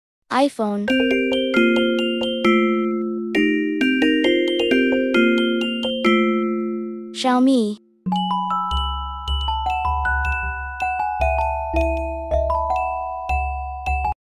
marble music